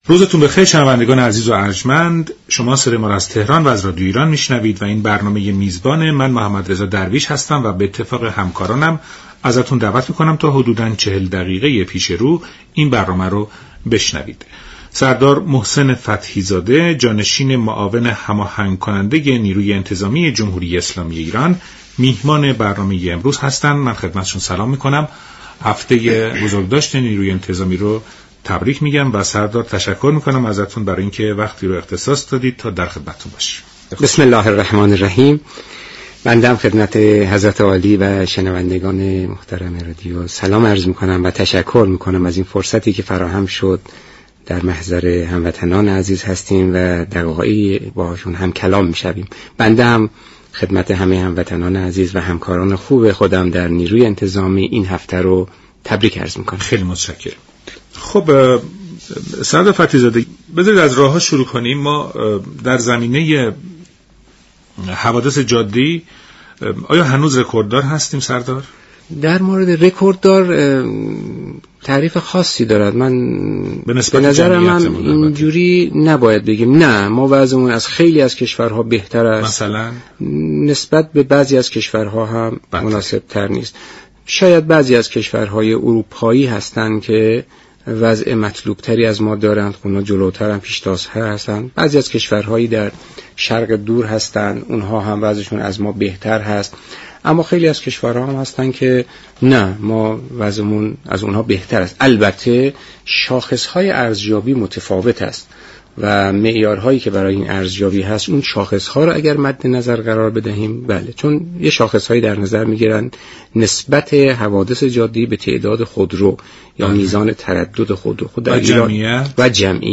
به گزارش شبكه رادیویی ایران سردار محسن فتحی زاده جانشین معاون هماهنگ كننده ناجا در گفت و گو با برنامه «میزبان» ضمن تبریك هفته نیروی انتظامی، درباره وضعیت جاده های كشور گفت: در بخش حوادث جاده ای اگرچه اروپا و كشورهای شرق آسیا در جایگاه بهتری قرار دارند اما ایران در مقایسه با دیگر كشورهای دیگر وضعیت مناسب تری دارد.